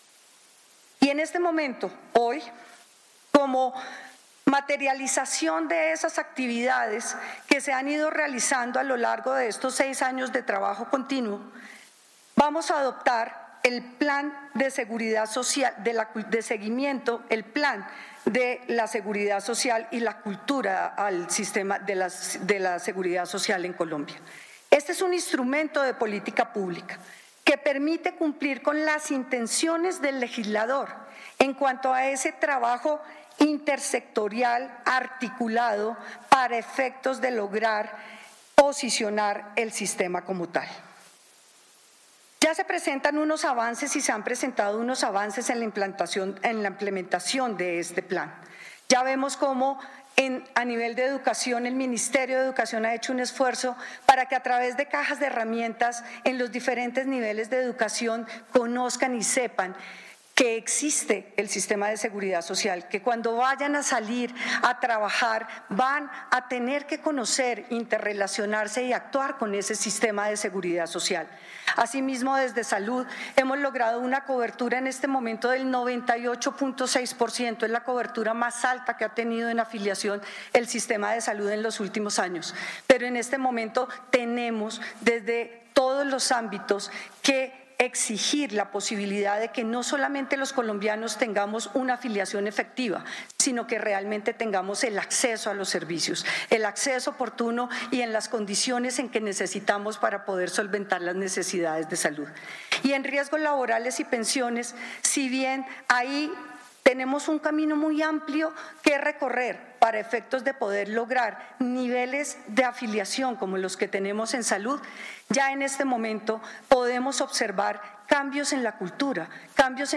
Audio de la viceministra de Protección Social, María Andrea Godoy Casadiego.